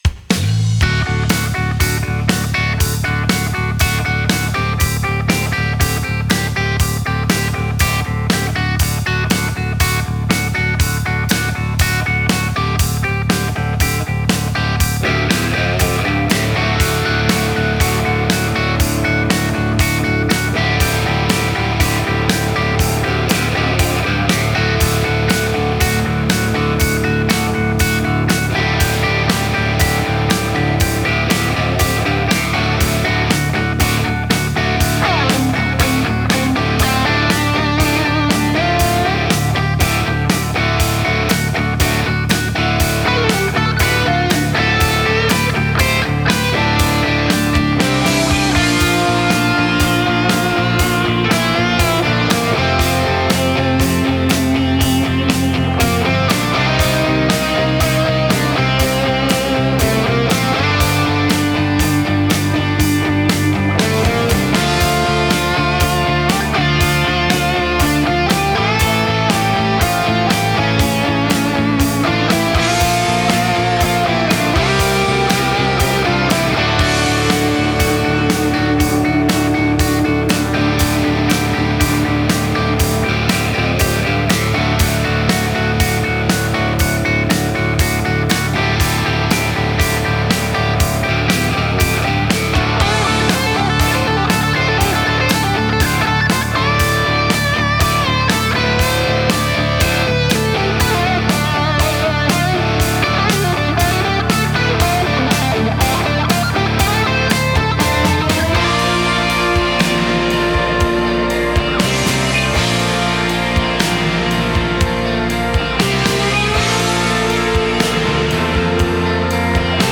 Record rhythm, lead or ambient guitar for your song
Fractal Audio AxeFX3
Guitars from Gibson, Fender and PRS
Solo Lead Acoustic Electric Guitar